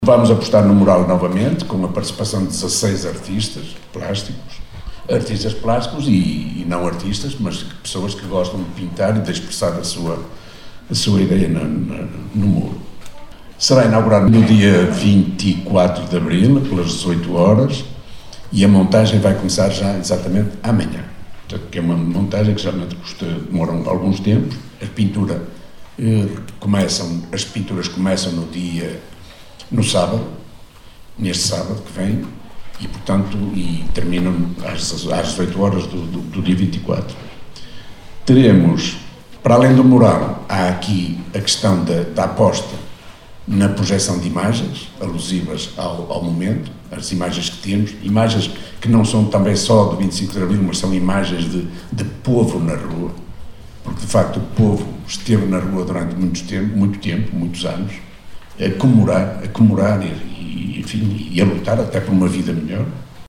Declarações